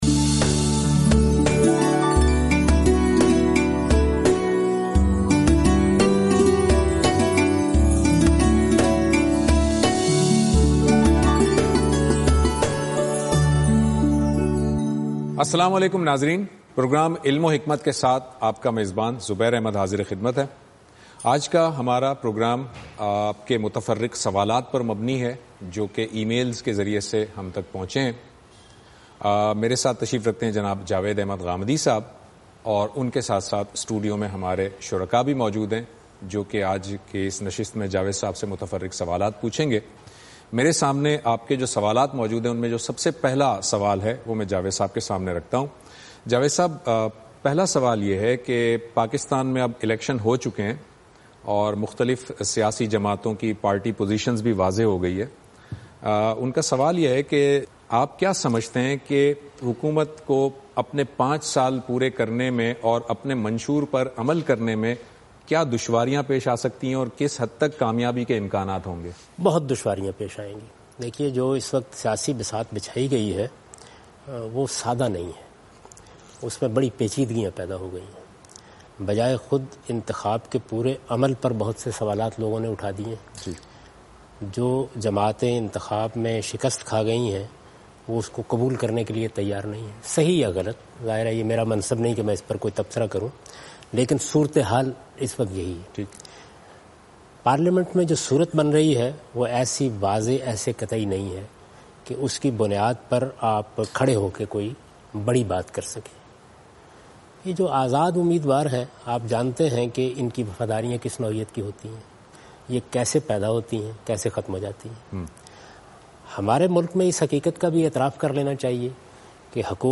In this program Javed Ahmad Ghamidi answers miscellaneous questions.